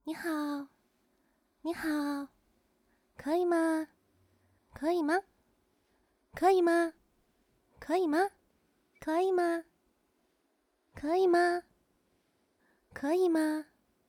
挥手请求.wav
挥手请求.wav 0:00.00 0:14.09 挥手请求.wav WAV · 1.2 MB · 單聲道 (1ch) 下载文件 本站所有音效均采用 CC0 授权 ，可免费用于商业与个人项目，无需署名。
人声采集素材/人物休闲/挥手请求.wav